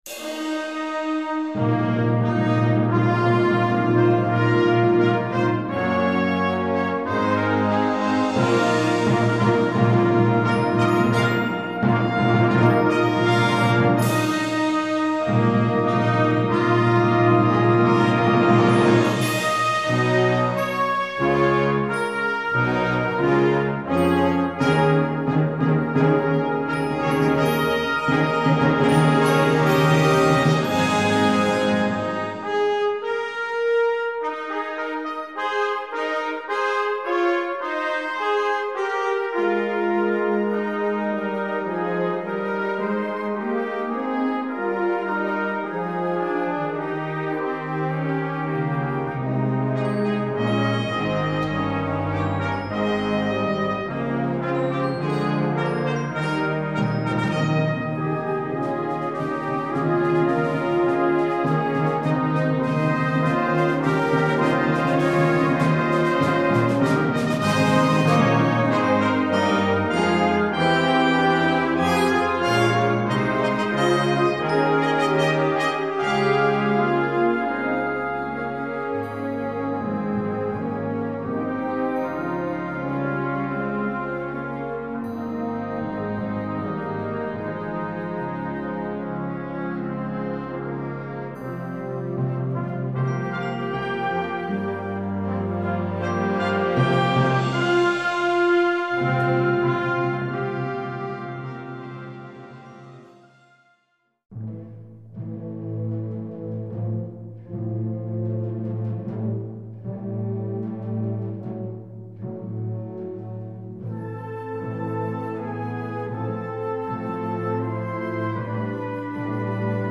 Recueil pour Harmonie/fanfare - Concert Band